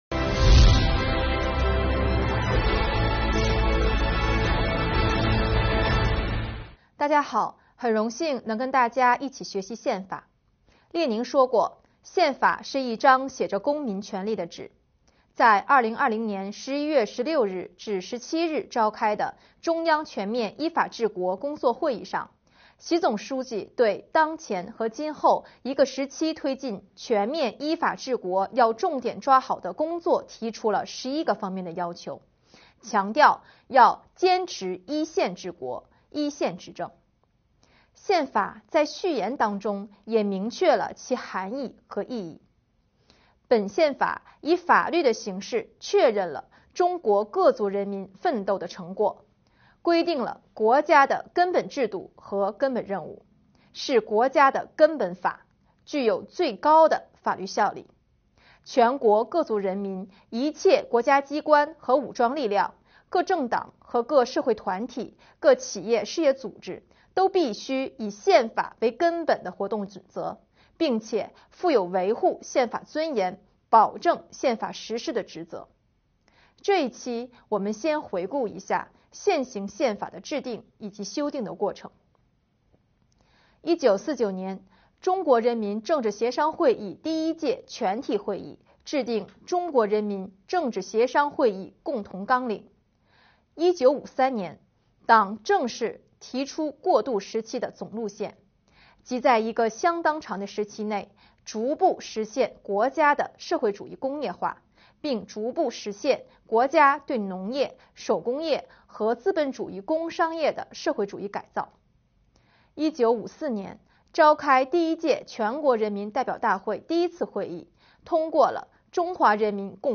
2021年第34期直播回放：宪法总论（上）